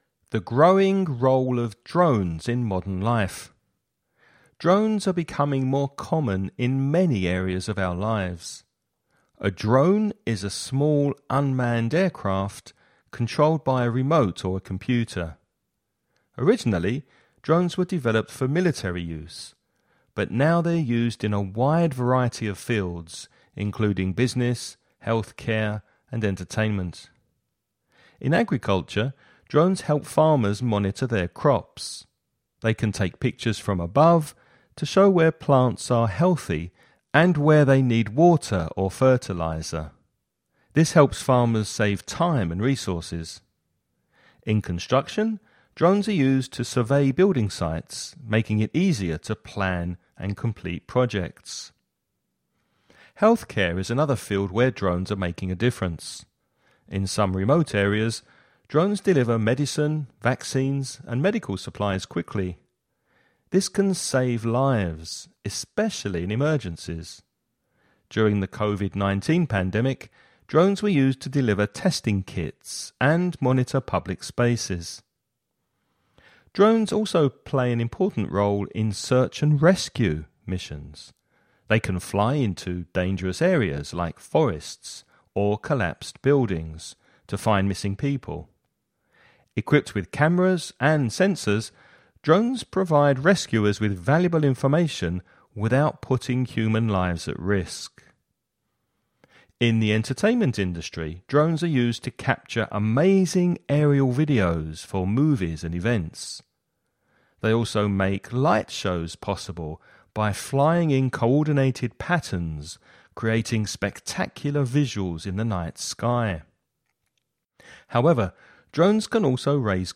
You’re going to listen to a man talking about drones.